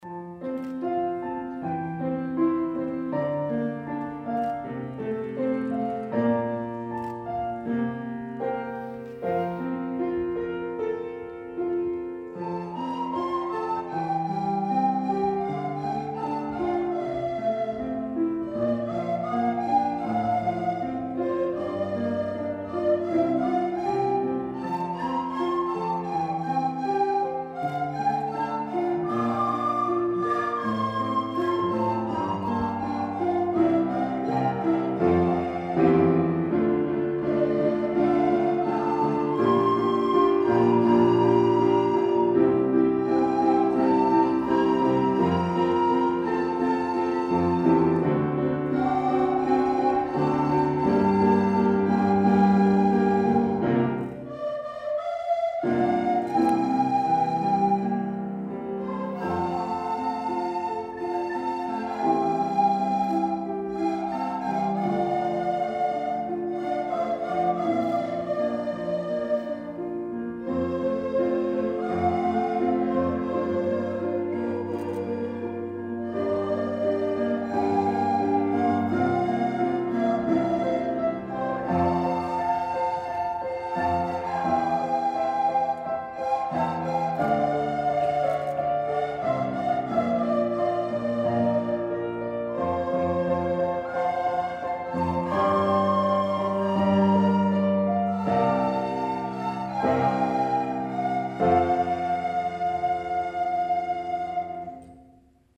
クラス合唱　音楽科発表
平成30年度 学習発表会 3-2合唱